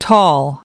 9 tall (adj) /tɔːl/ Cao